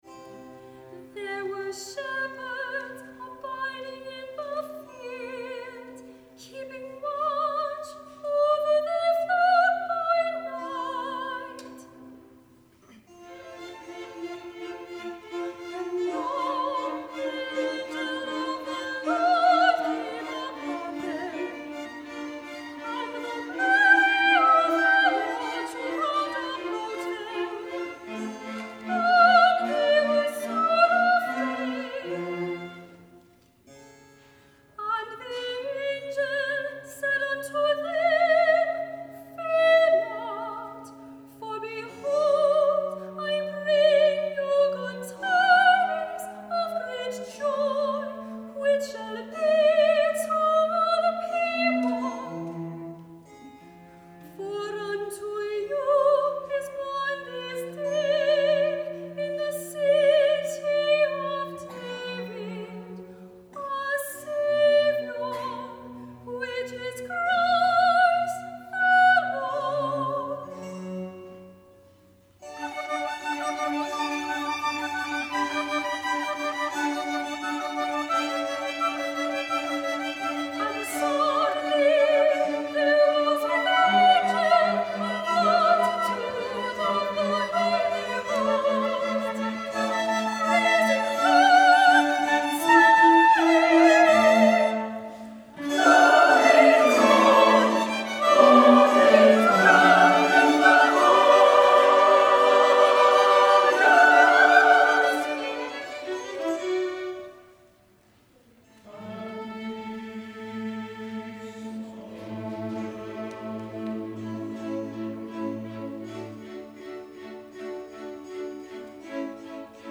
On December 12 we once again held our annual Lessons & Carols service.
soprano